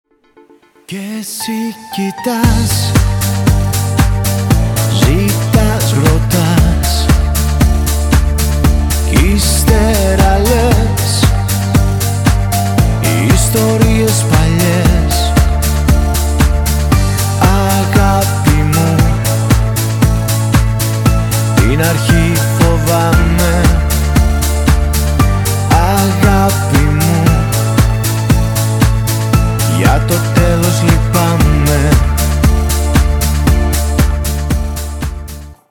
• Качество: 192, Stereo
поп
грустные
приятные